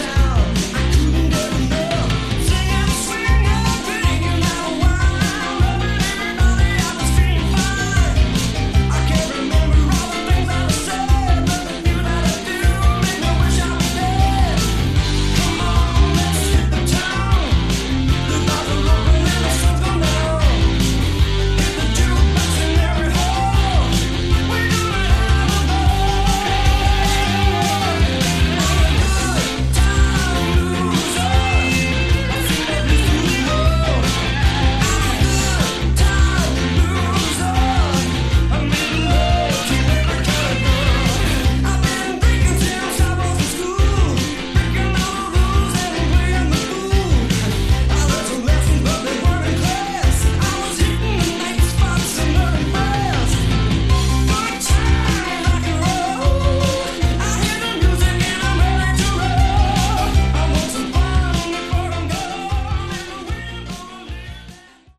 Category: Hard Rock
vocals
guitar
bass
Keys
drums